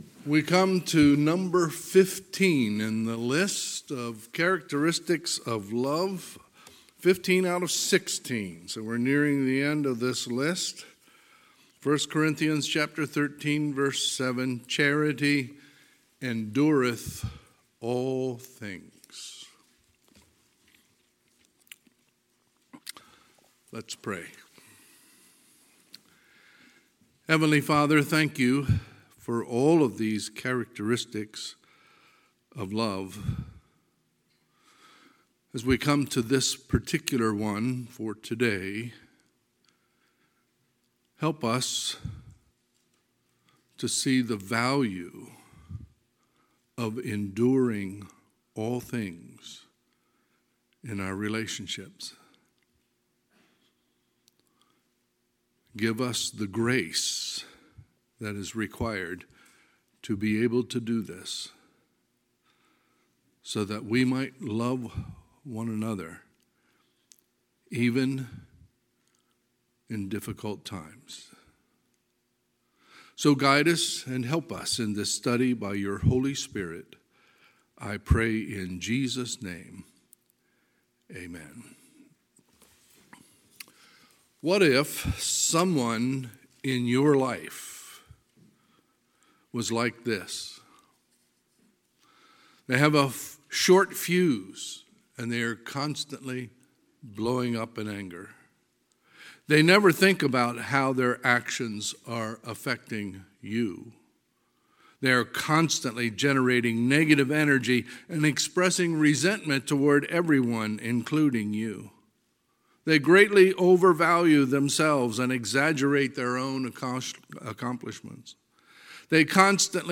Sunday, October 31, 2021 – Sunday AM